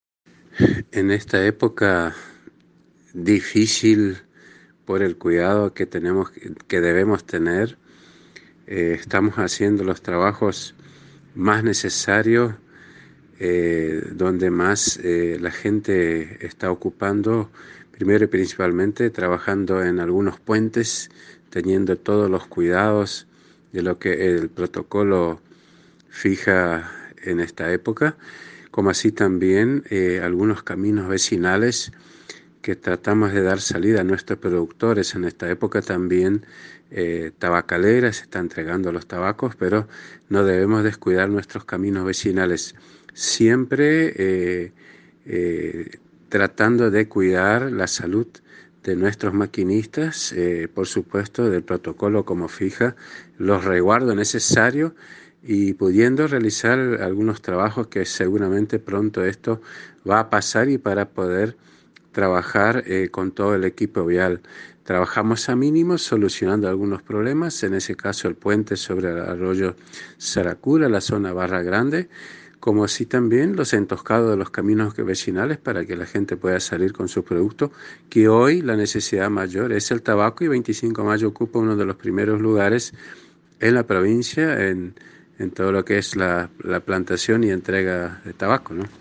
En diálogo con la Agencia de Noticias Guacurarí el Intendente Mario Lindemann brindó detalles de los trabajos realizados,